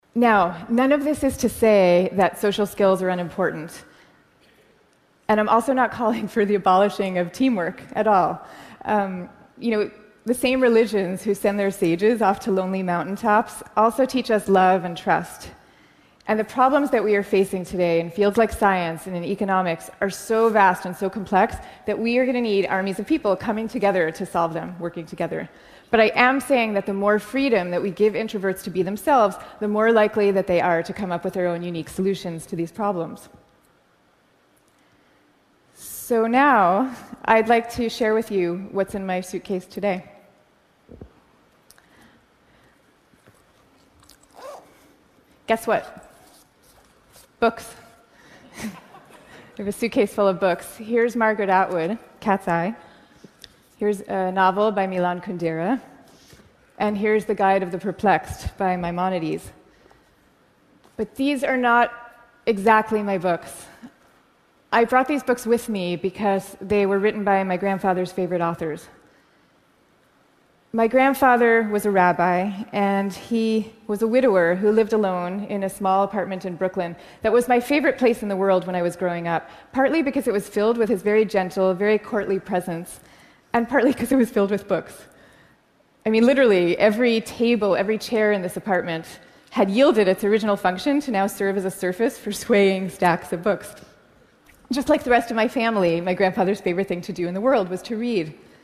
TED演讲：内向性格的力量(9) 听力文件下载—在线英语听力室